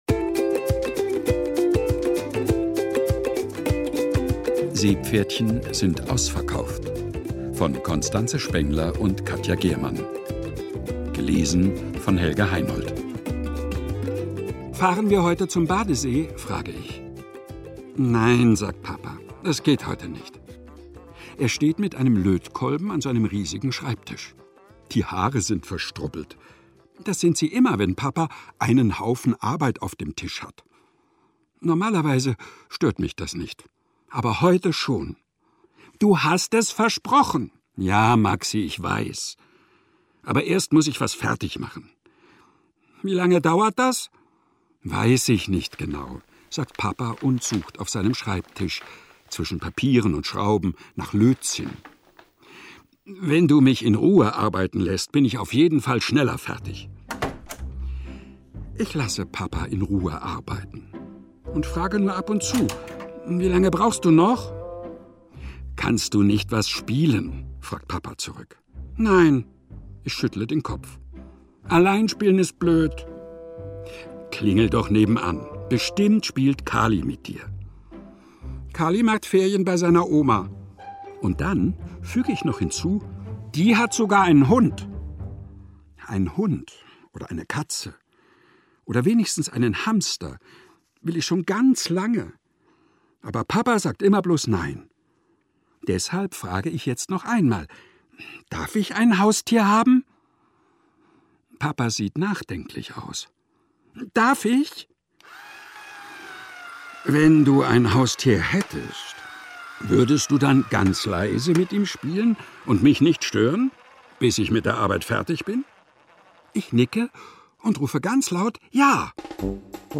Lese- und Medienproben
Seepferdchen sind ausverkauft ... und zwei Stadtbär-Geschichten. Ungekürzte szenische Lesungen mit Musik